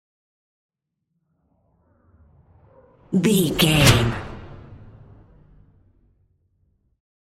Whoosh super fast
Sound Effects
Fast
futuristic
intense
whoosh